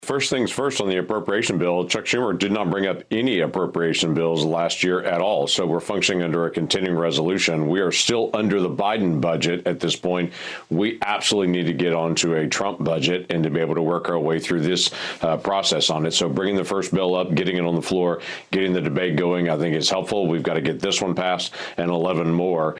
On Wednesday morning, Oklahoma Senator James Lankford spoke to Fox Business on a looming government shutdown that is just over 60 days away.